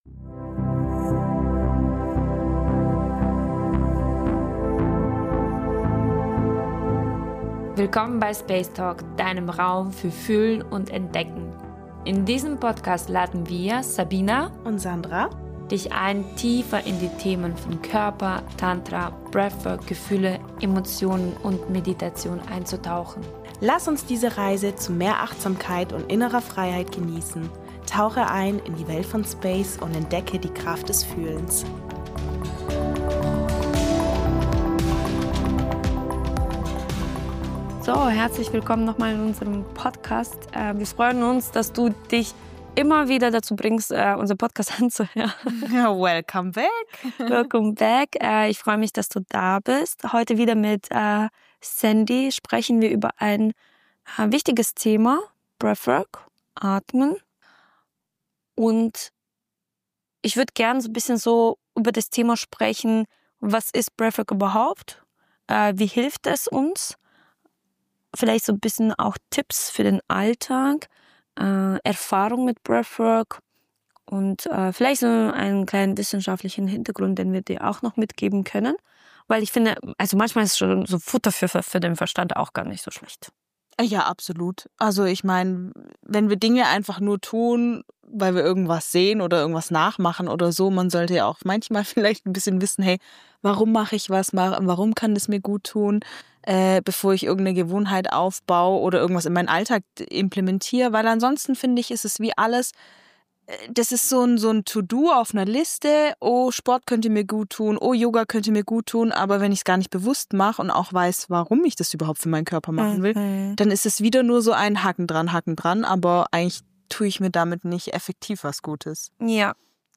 In dieser Episode nehmen wir dich live in eine kurze tantrische Atemübung mit – eine kraftvolle Methode, um deine Energie zu aktivieren und den Geist zu klären.